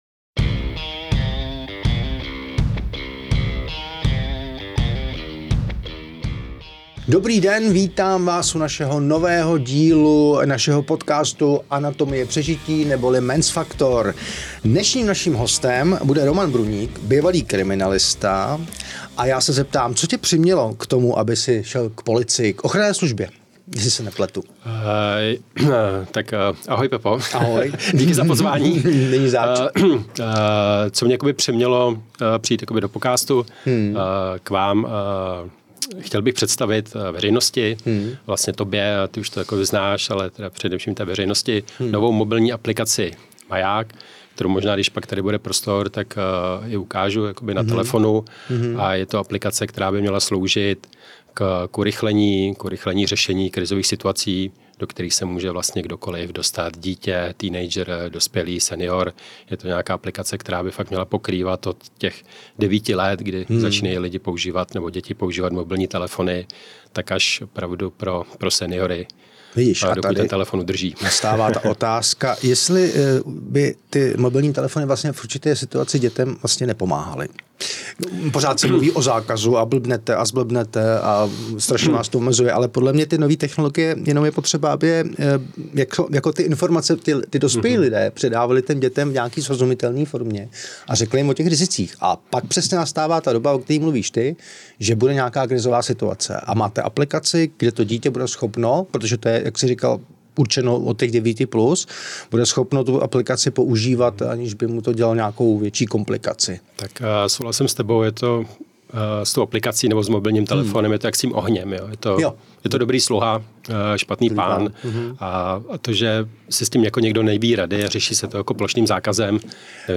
V rozhovoru pro podcast Men’s Factor vysvětluje, jak aplikace funguje, proč umí odesílat tichou SOS zprávu s přesnou polohou, jak pomáhá dětem, seniorům i rodičům a proč by technologie neměly být strašákem, ale nástrojem prevence.